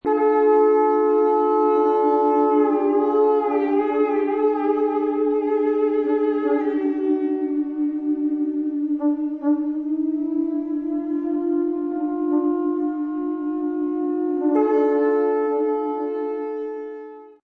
Conch Shells – sounds